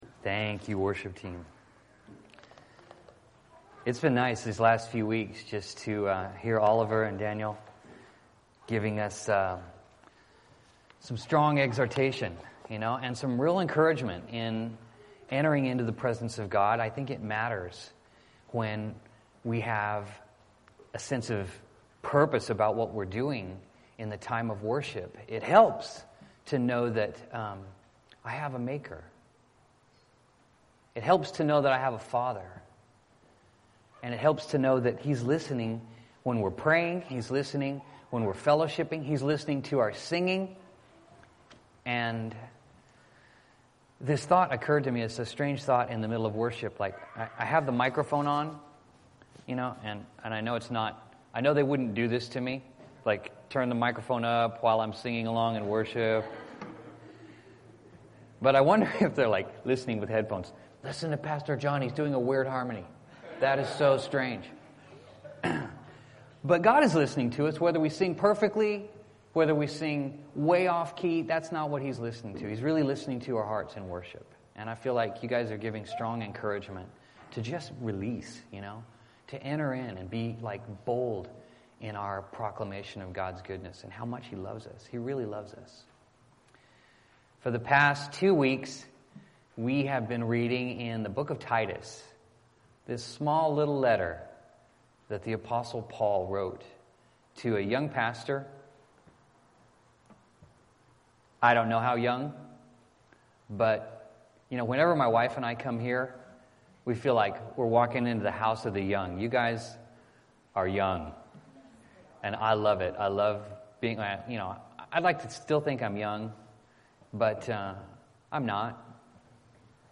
Bible Text: Titus 3 | Preacher